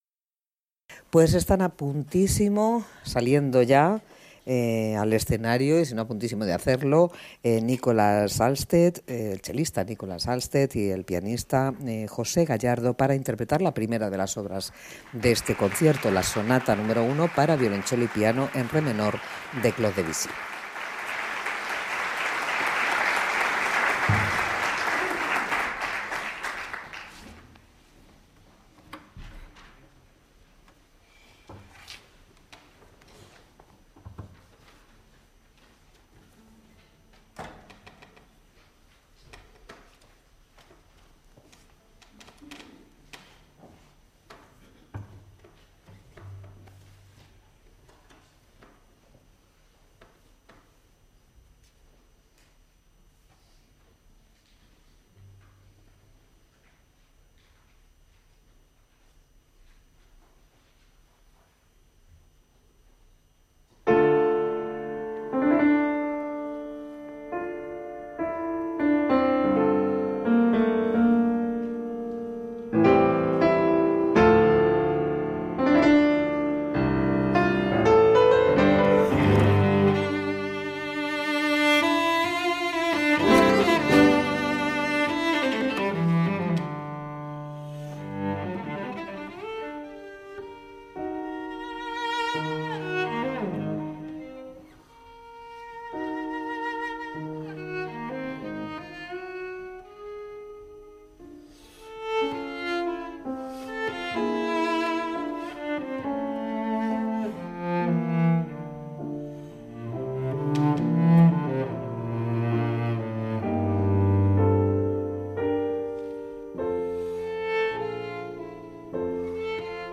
Recital in Madrid
Cello recitals this week.
French-German virtuoso cellist
at the piano